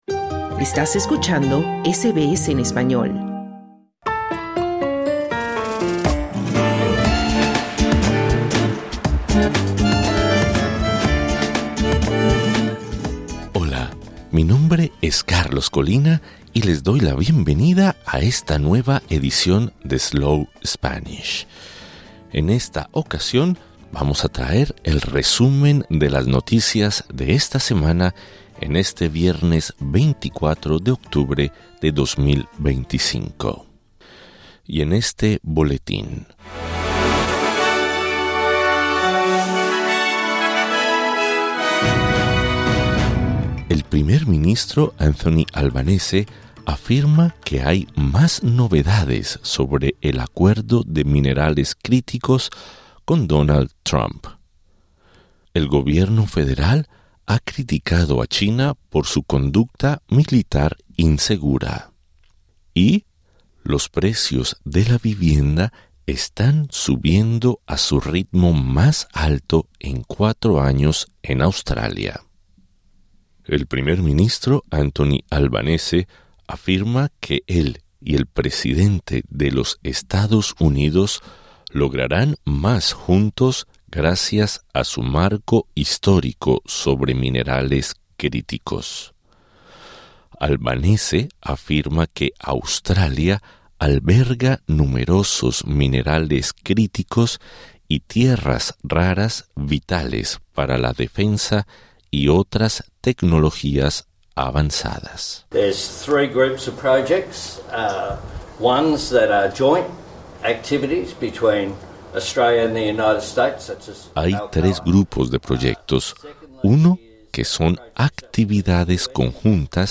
Welcome to SBS Slow Spanish, a new podcast designed in Australia specifically for those interested in learning the second most spoken language in the world. This is our weekly news flash for October 24th, 2025.